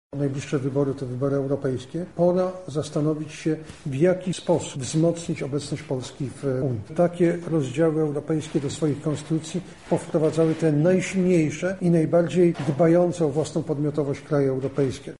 -mówi Jarosław Gowin, minister nauki i szkolnictwa wyższego.